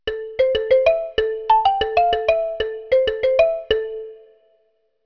ringin.wav